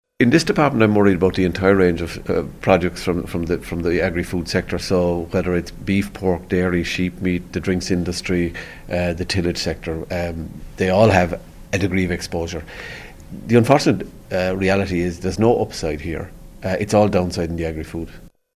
Minister Creed says there will be a huge risk to farmers and fishermen from Brexit: